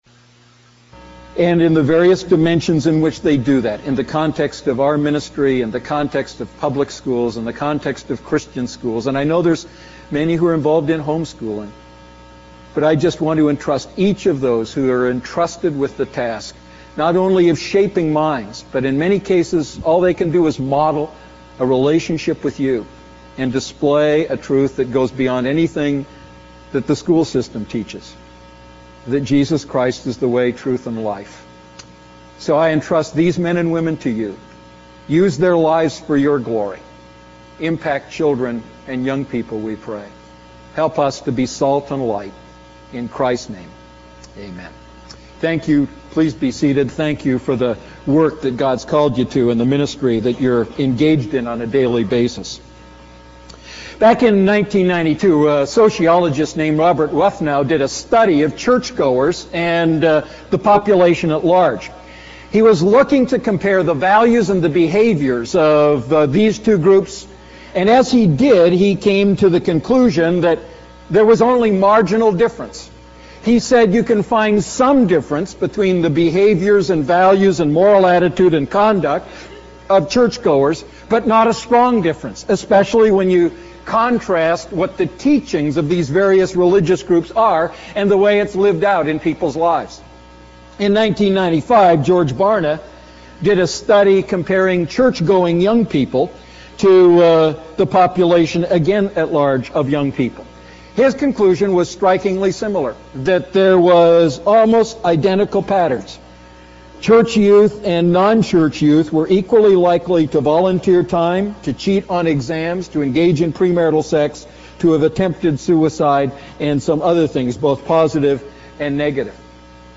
A message from the series "Holy Spirit."